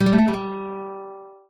guitar_gag.ogg